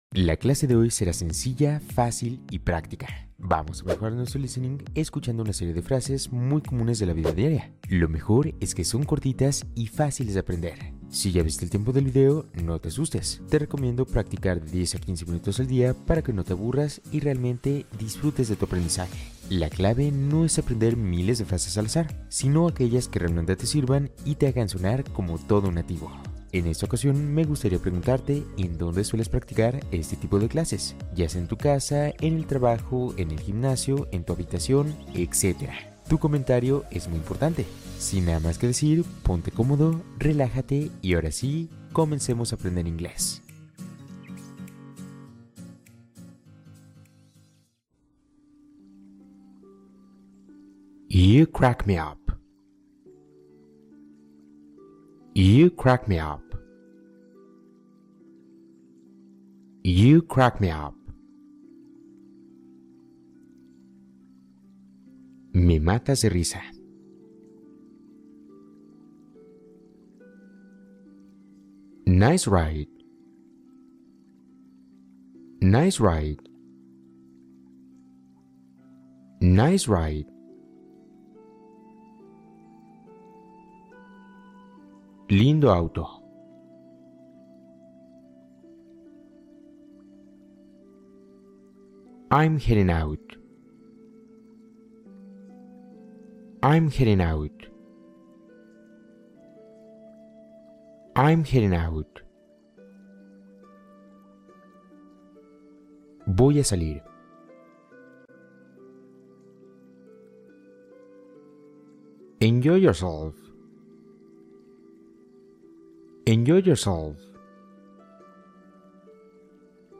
Sesión de relajación con práctica de listening para mejorar tu inglés